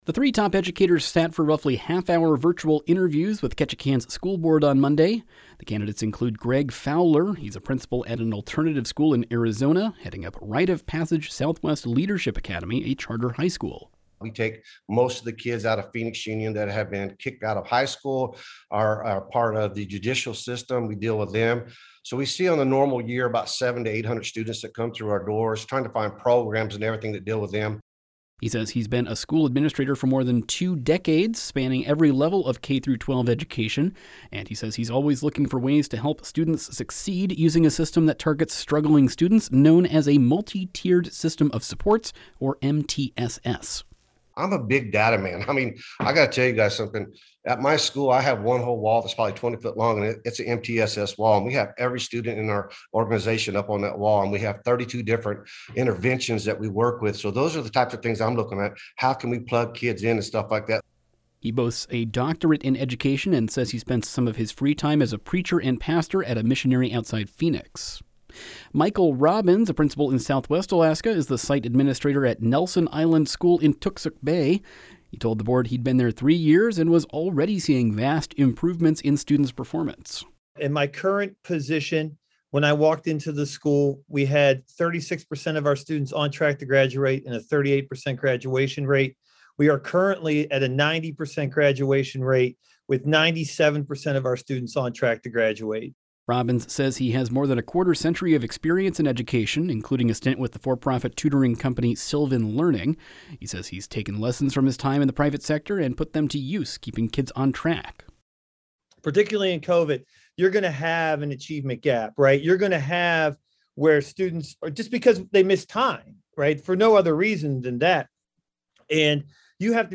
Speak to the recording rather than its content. The three top educators sat for roughly half-hour virtual interviews with Ketchikan’s school board on Monday.